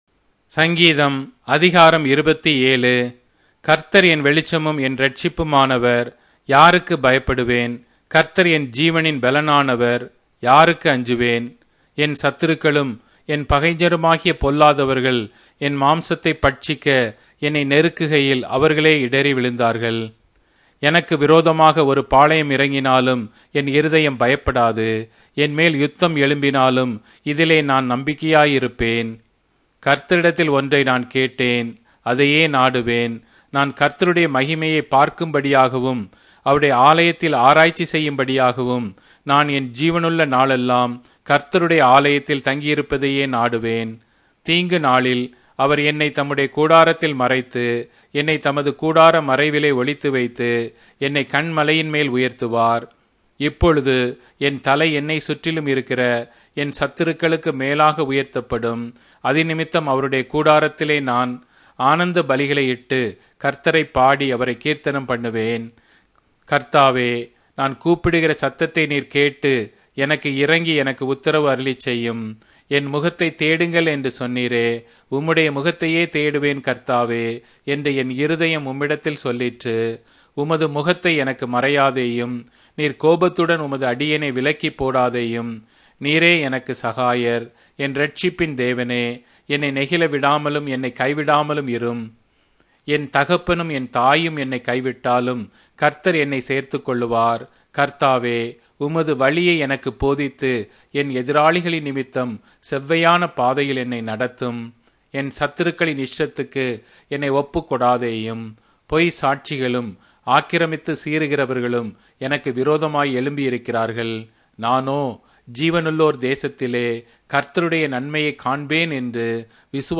Tamil Audio Bible - Psalms 93 in Gnterp bible version